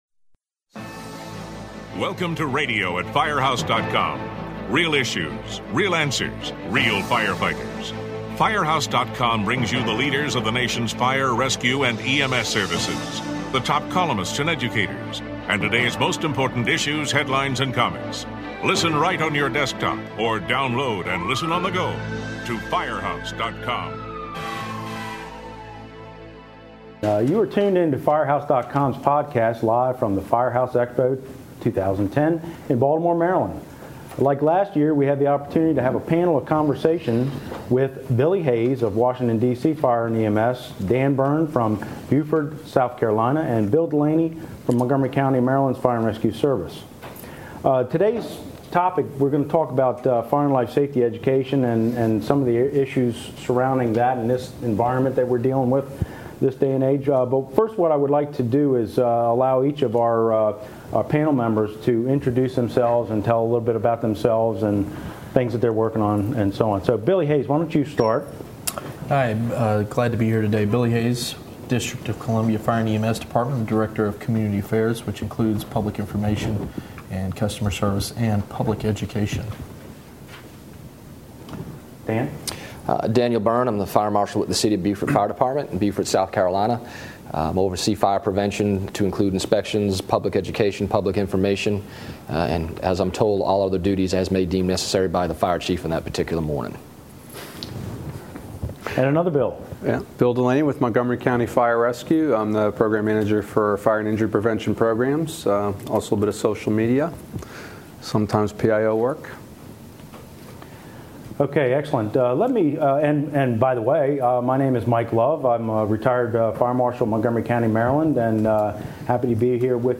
This podcast was recorded at Firehouse Expo in July.